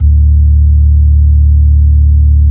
Index of /90_sSampleCDs/Keyboards of The 60's and 70's - CD2/B-3_Club Slow/B-3_Club Slow